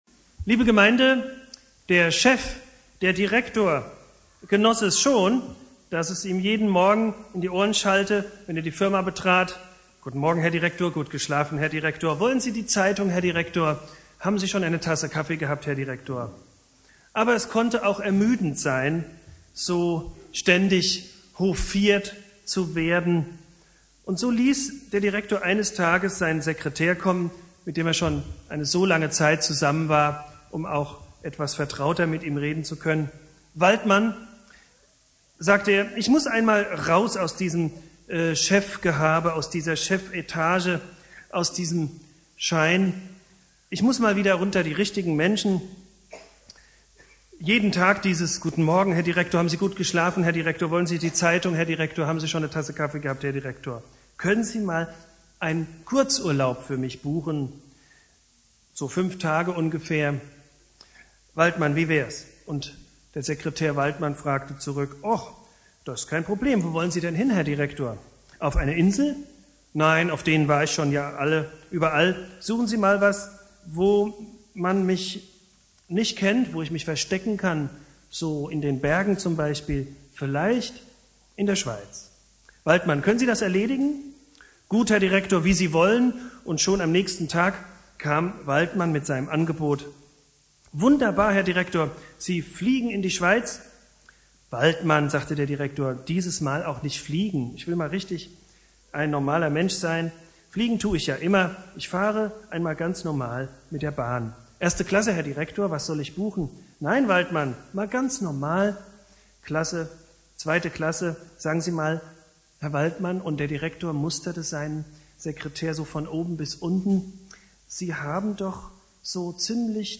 Predigt
Heiligabend Prediger